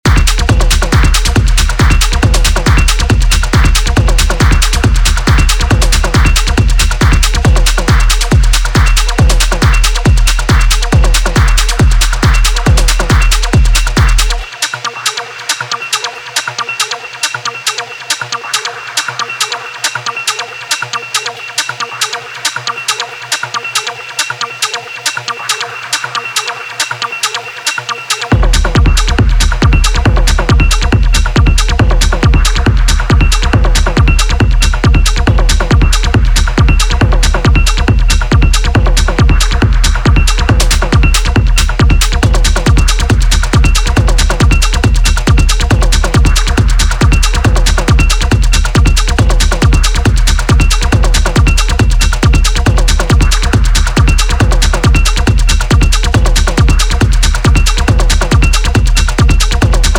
raw and hypnotic